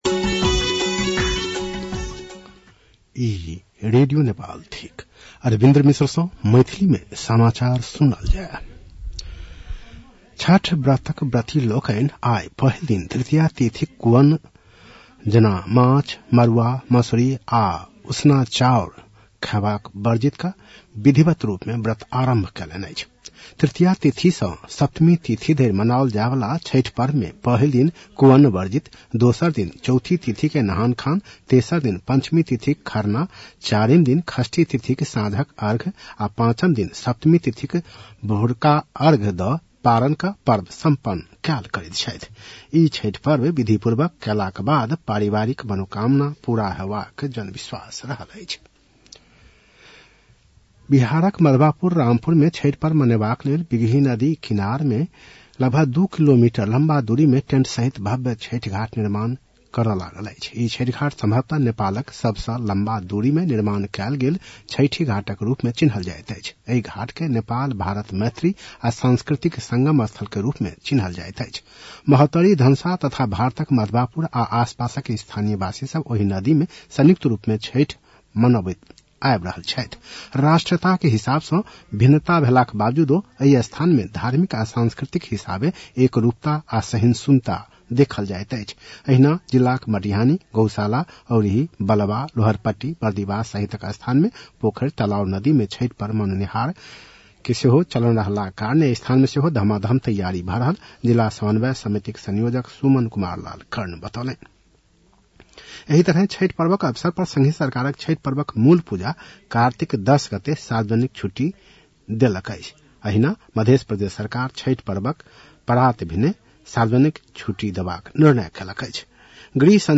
मैथिली भाषामा समाचार : ७ कार्तिक , २०८२
Maithali-news-7-07.mp3